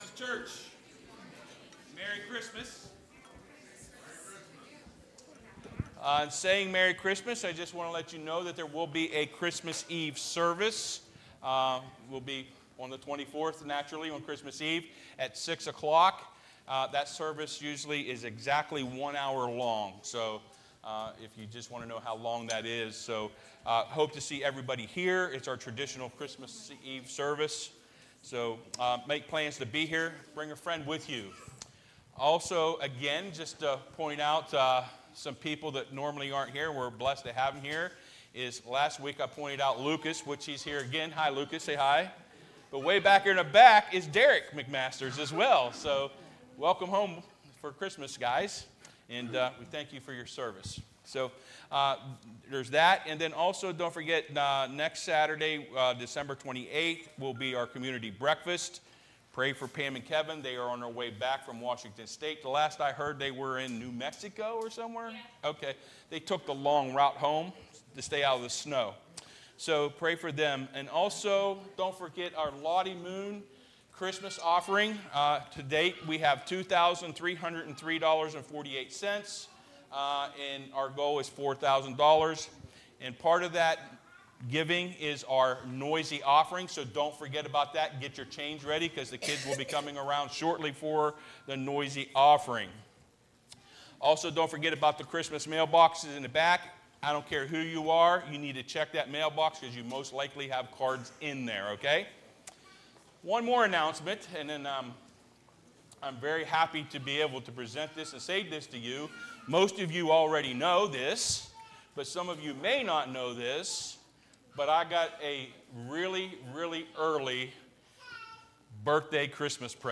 (Sermon starts at 23:00 in the recording).